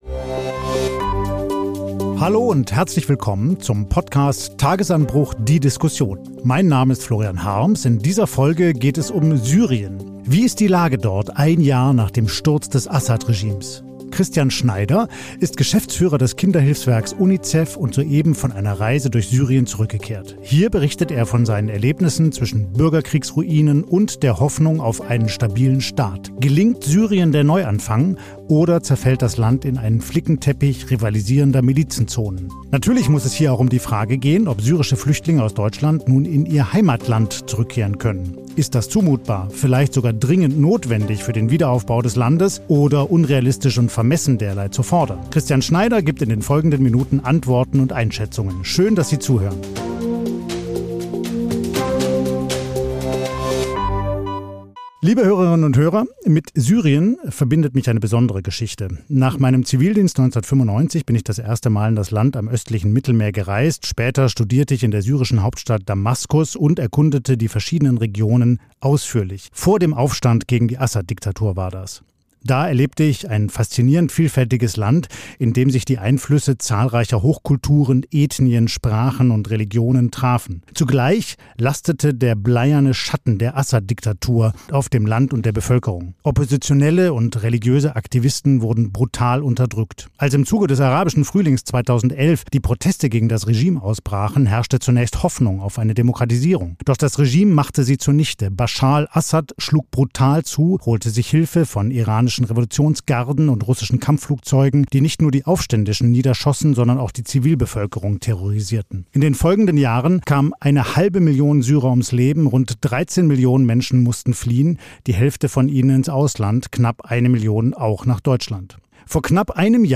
Tagesanbruch – die Diskussion